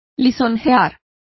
Complete with pronunciation of the translation of flatters.